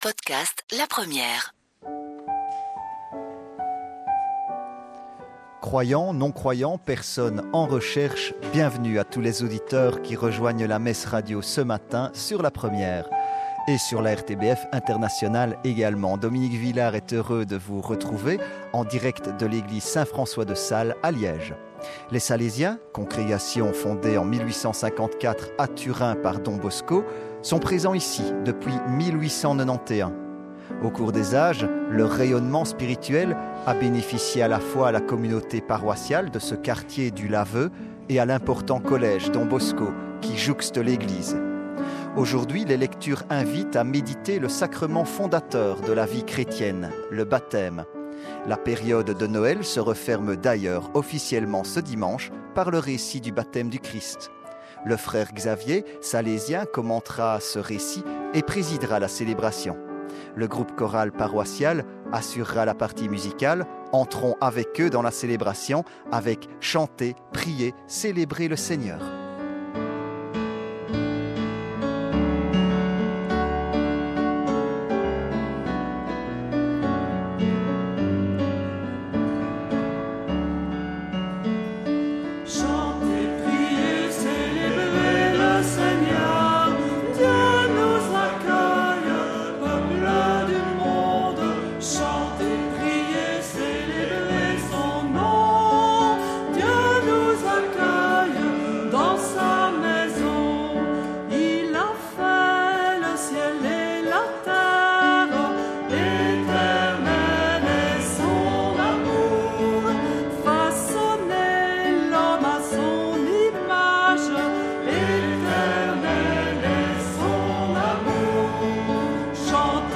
Messe 10 janvier 2016
Radiodiffusée sur la RTBF – La Première